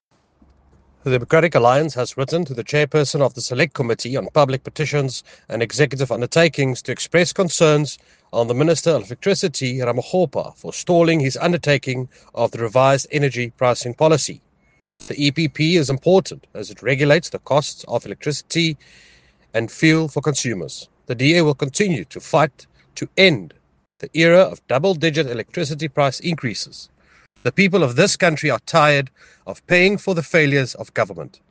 Afrikaans soundbites by Nico Pienaar MP.